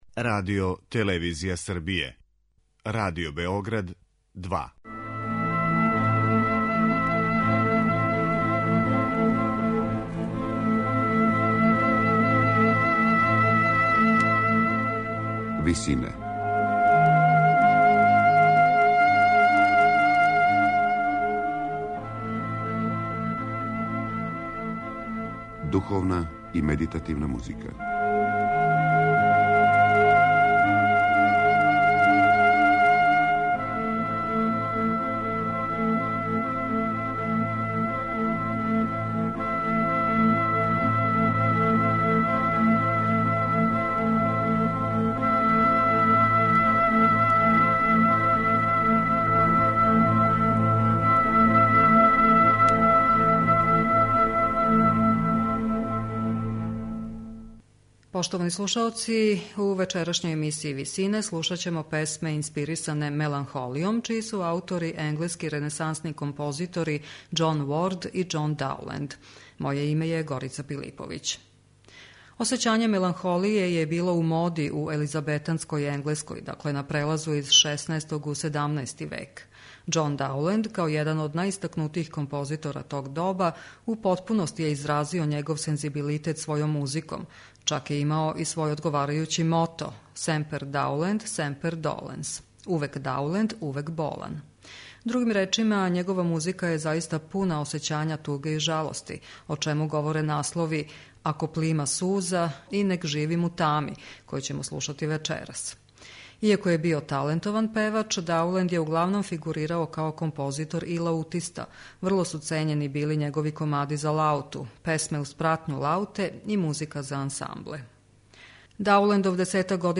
Песме инспирисане меланхолијом
На крају програма, у ВИСИНАМА представљамо медитативне и духовне композиције аутора свих конфесија и епоха.
Слушаћете песме инспирисане меланхолијом, чији су аутори енглески ренесансни композитори Џон Ворд и Џон Дауленд.